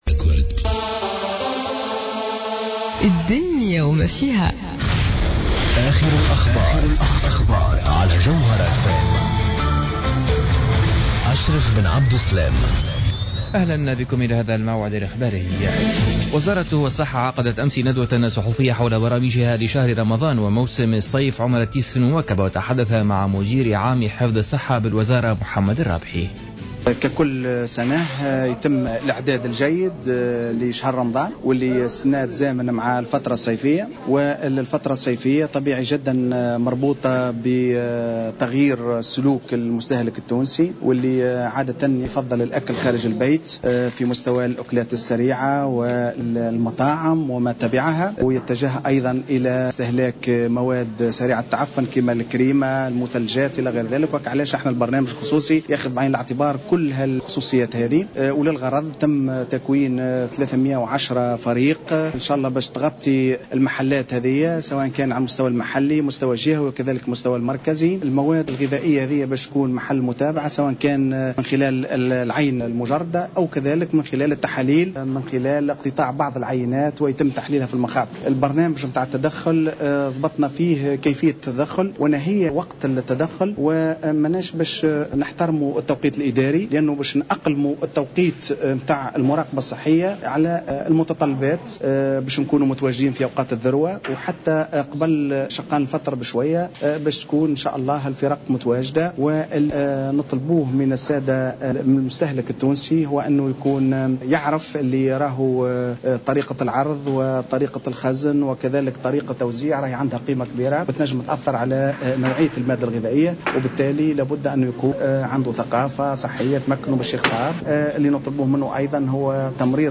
نشرة أخبار منتصف الليل ليوم الخميس 18 جوان 2015